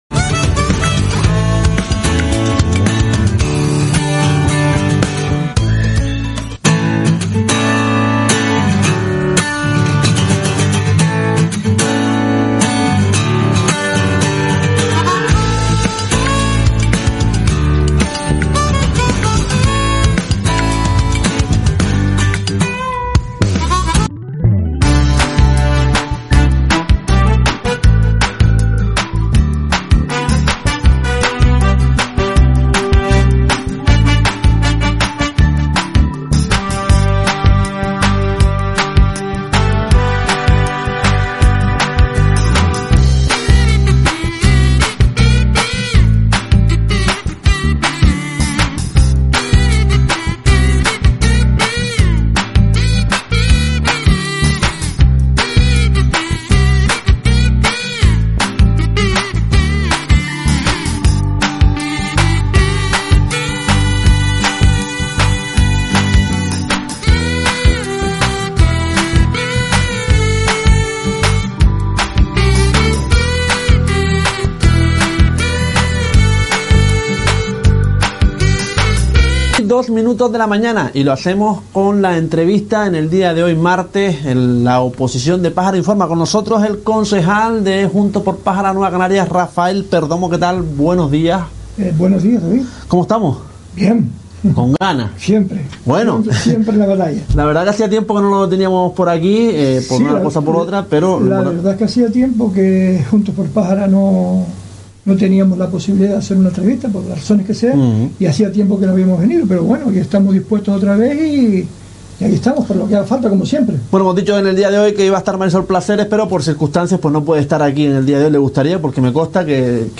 Los martes es el turno de la Oposición de Pájara, en esta ocasión nos visitó Rafael Perdomo, concejal de Juntos X Pájara, dando un amplio repaso de como ve su formación política el municipio de Pájara.